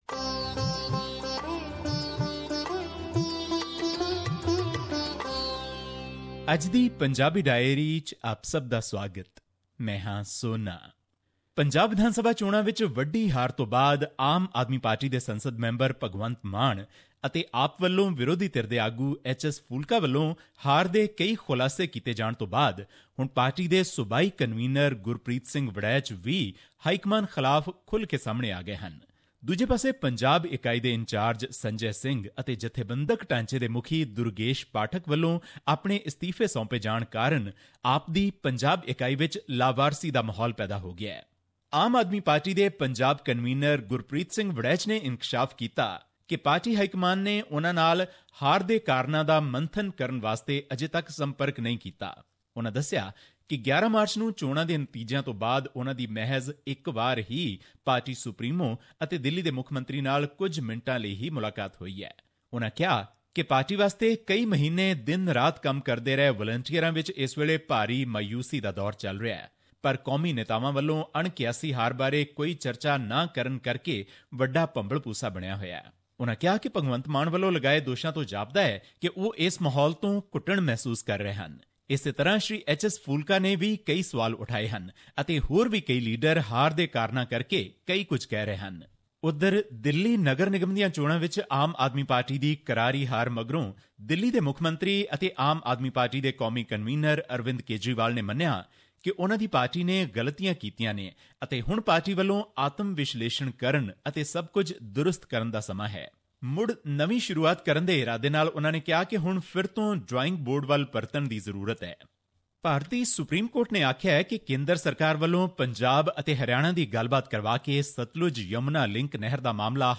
His report was presented on SBS Punjabi program on Monday, May 01, 2017, which touched upon issues of Punjabi and national significance in India. Here's the podcast in case you missed hearing it on the radio.